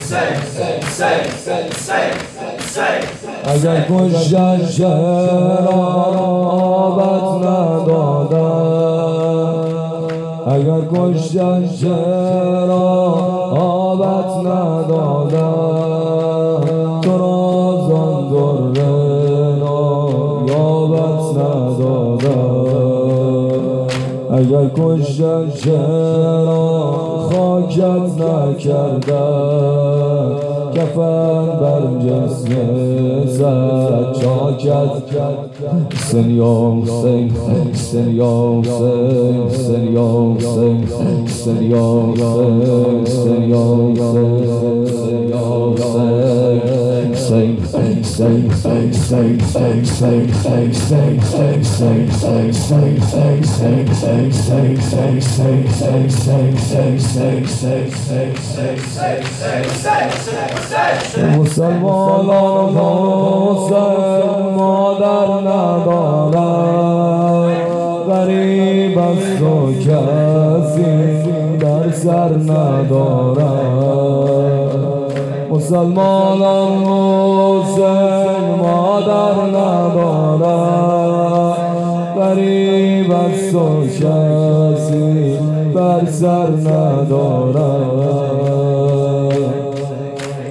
شور-اگر-کشتند-چرا-آبت-ندادند.mp3